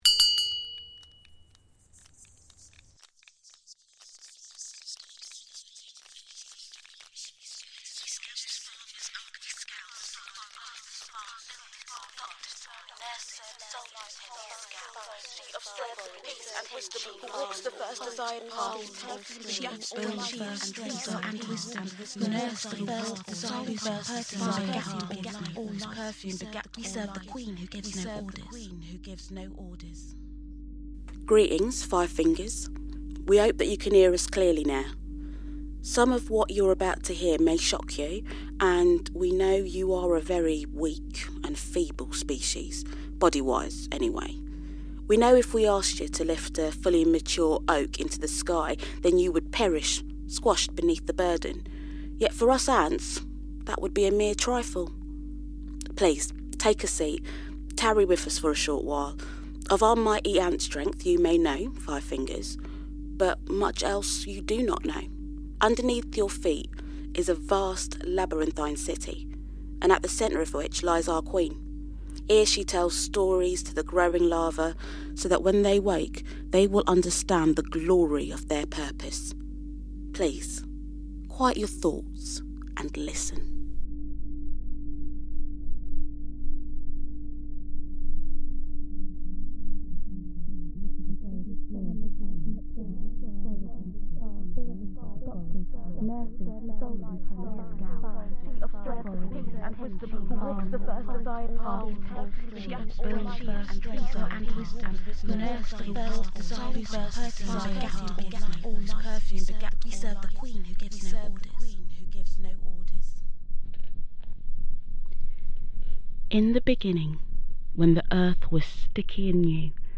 Lore of the Wild – an audio storytelling walk through the woods